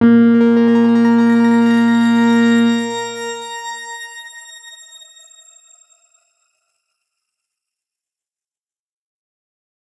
X_Grain-A#2-ff.wav